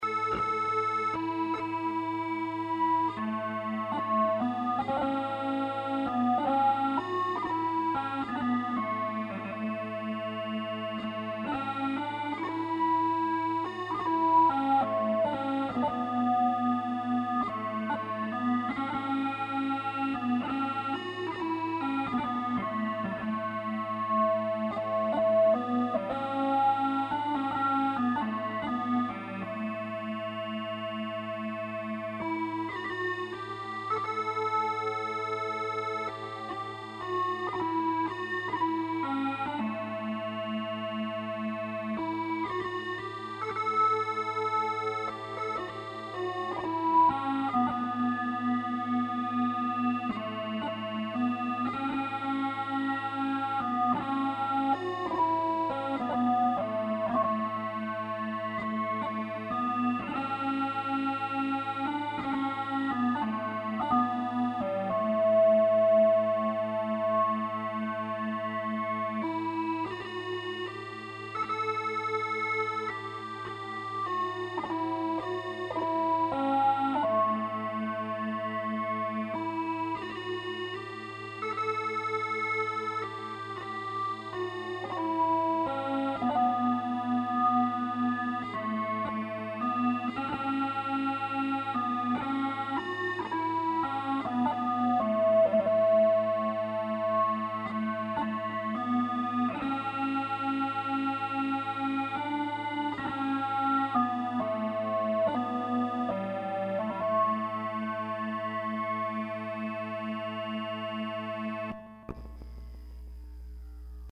Bagpipe Sound Samples
a Deger Pipe electronic chanter
using a Creative Lab Surround Mixer for effects